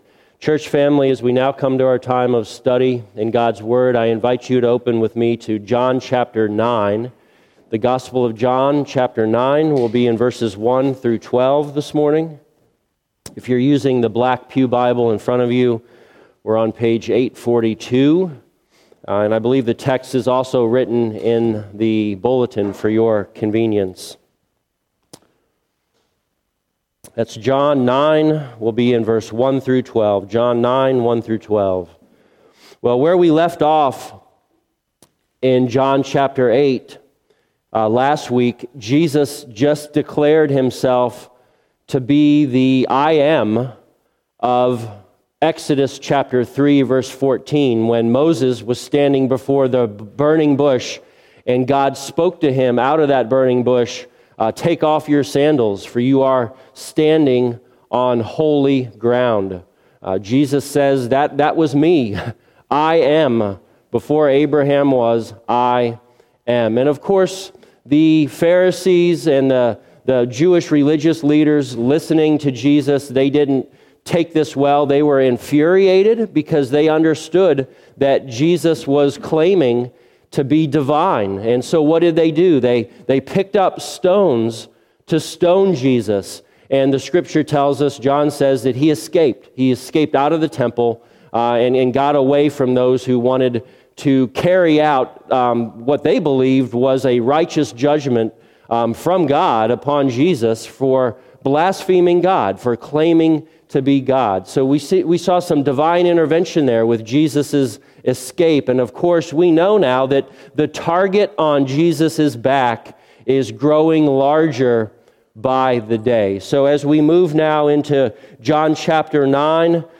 Passage: John 9:1-12 Service Type: Sunday Morning « Responding to Jesus’ Divine Claims From Darkness to Divine Light